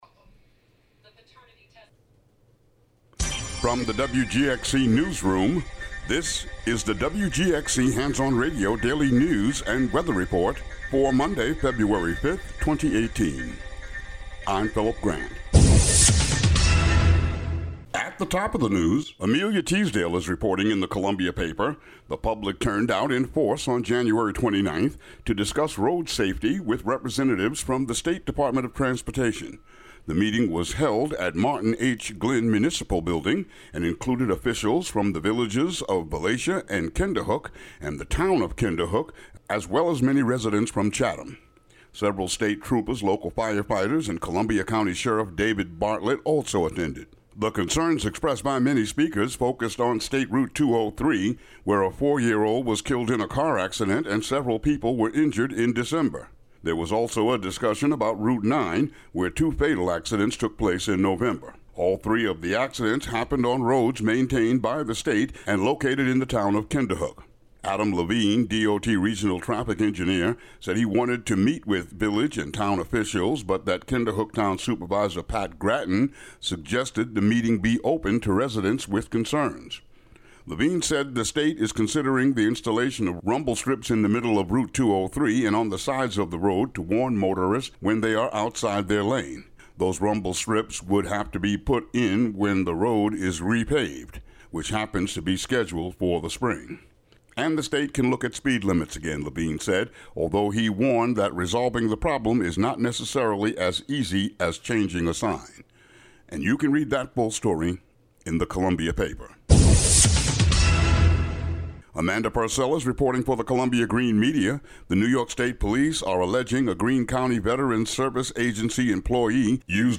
WGXC Local News